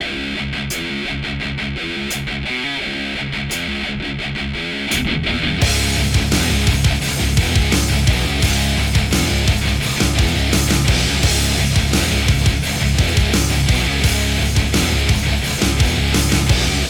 Электрогитара "Ibanez RG 350 EX Black" .
Несколько фрагментов с этой гитарой: